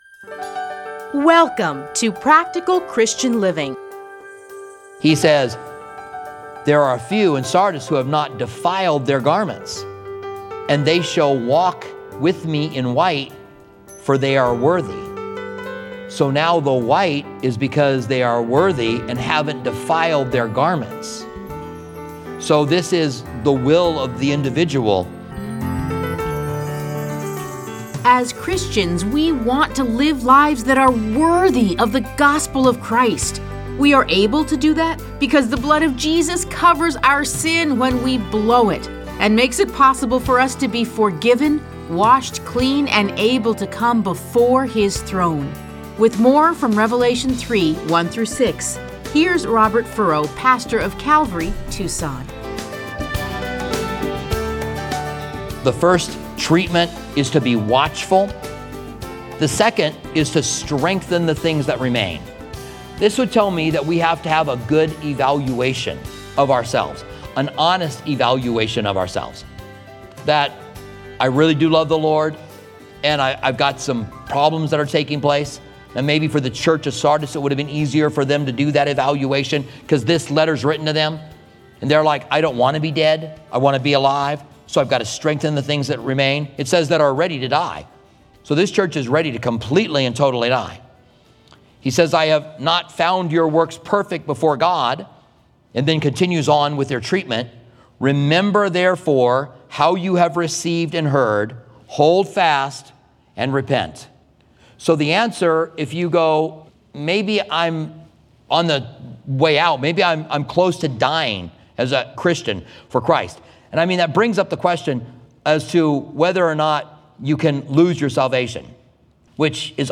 Listen to a teaching from Revelation 3:1-6.